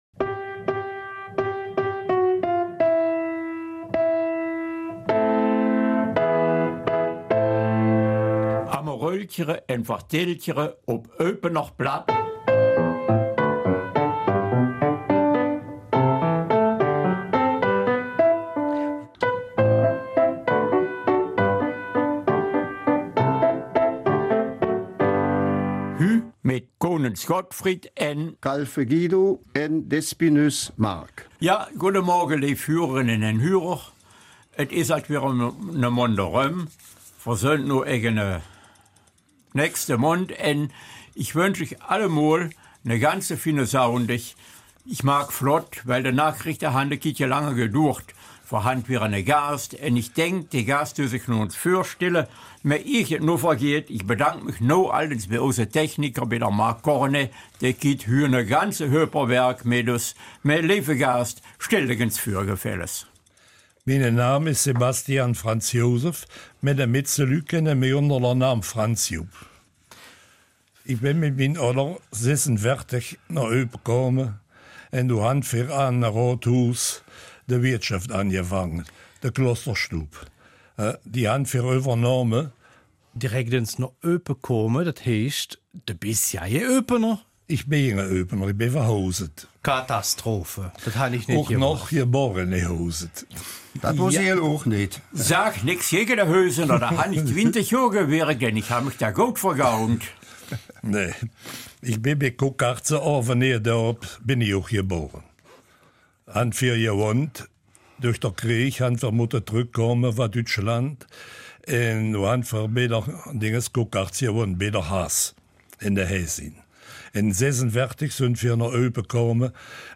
Eupener Mundart: Das Zapfen in die Wiege gelegt